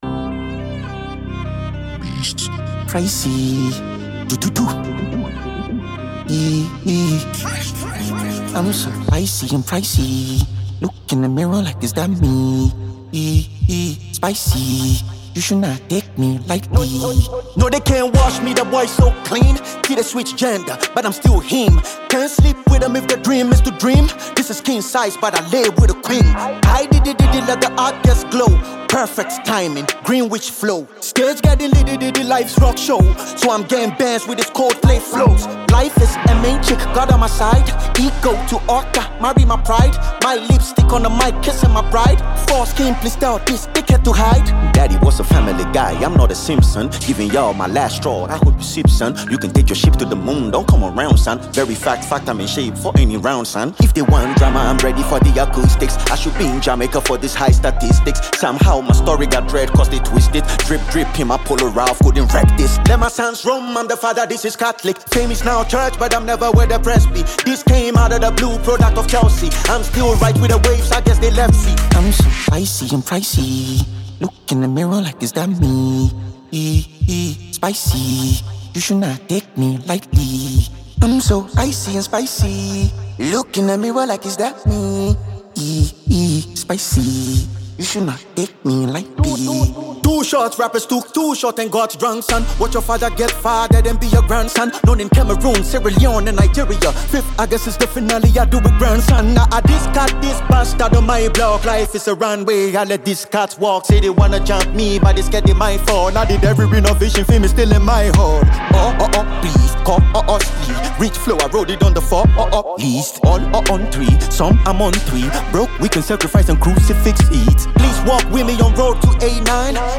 a Ghanaian rapper